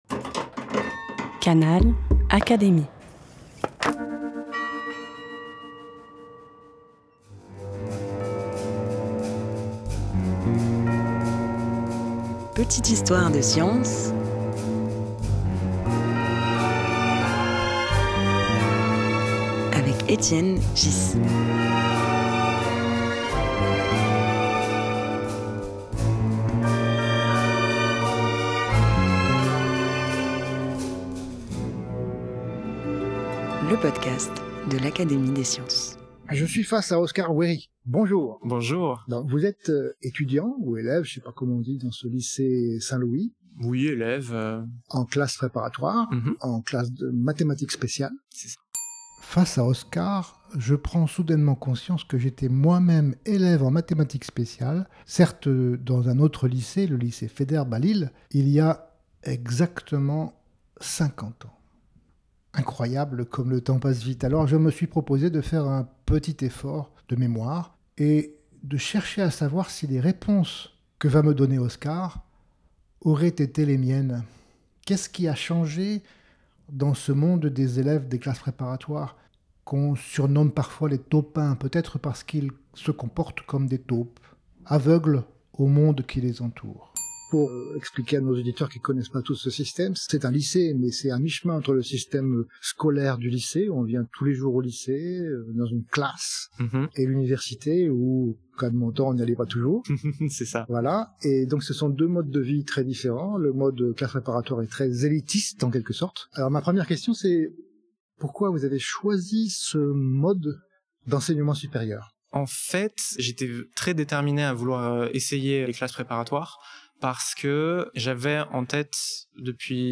Une rencontre intergénérationnelle qui éclaire les aspirations d’un jeune scientifique et soulève des questions sur l’évolution des méthodes d’enseignement et de la transmission des savoirs.
Un podcast animé par Étienne Ghys, proposé par l'Académie des sciences.